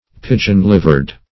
Pigeon-livered \Pi"geon-liv`ered\, a.
pigeon-livered.mp3